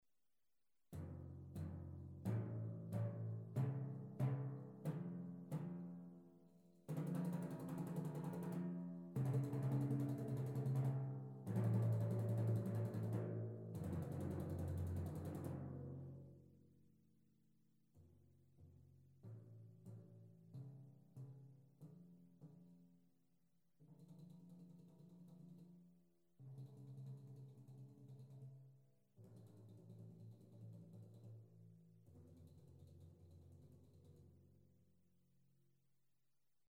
• 具沙鈴及定音鼓棒功能
• 音色試聽
JG 沙鈴鼓棒，顧名思義就是結合了沙鈴效果並有鼓棒的功能。